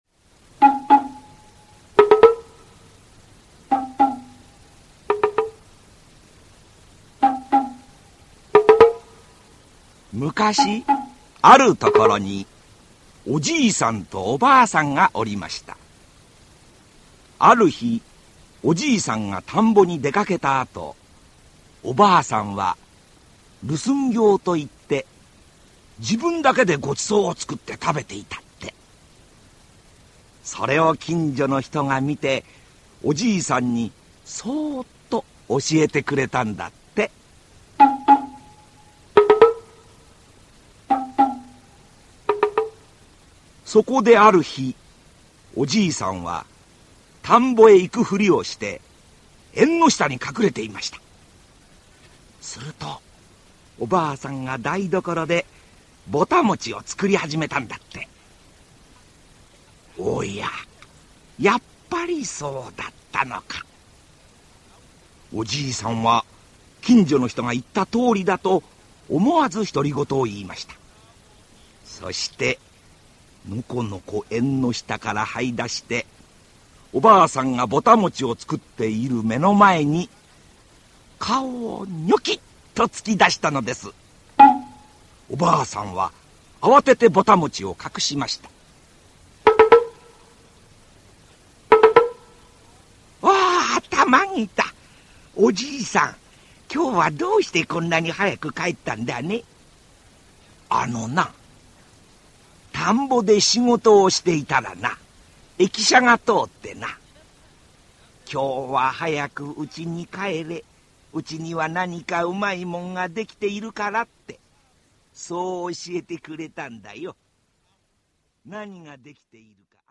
[オーディオブック] おじいさんのちえ